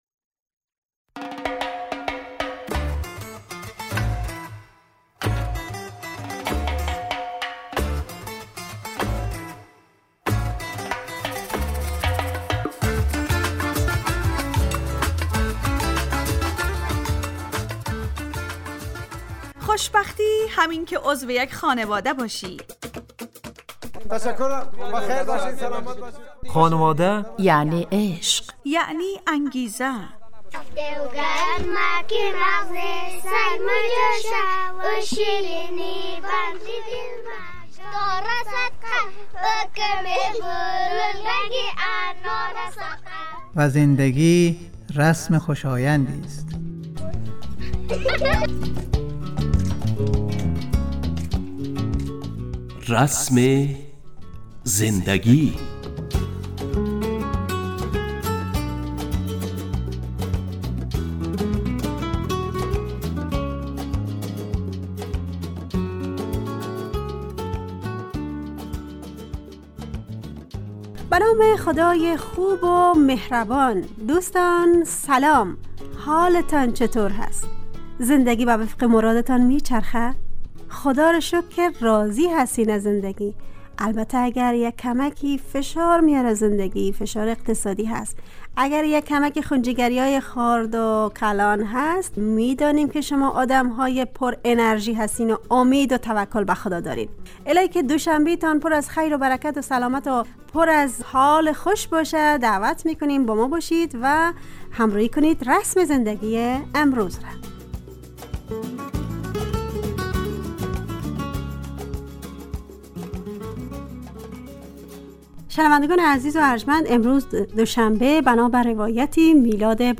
رسم زندگی_ برنامه خانواده رادیو دری ___ روشنبه 6 مرداد 404 ___موضوع_ خیرخواهی داشتن _ گوینده و تهیه کننده و میکس